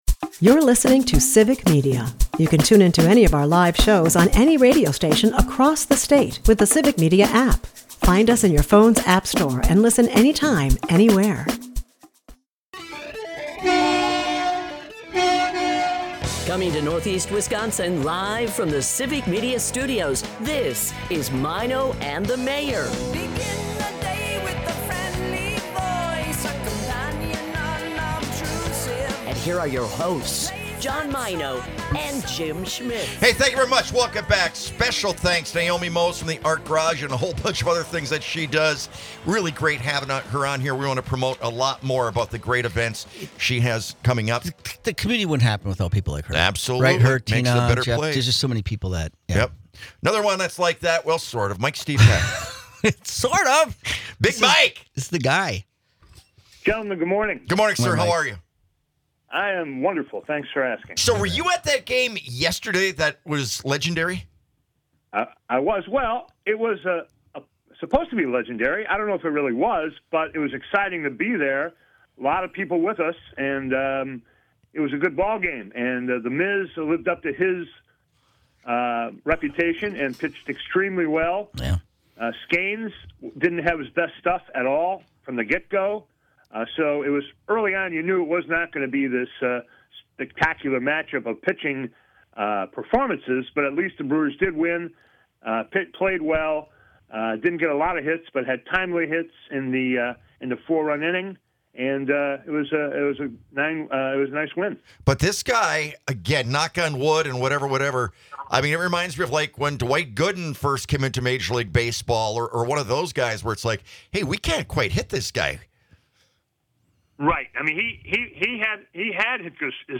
With humor and nostalgia, the hosts reminisce about church and choir experiences while promoting this great upcoming event.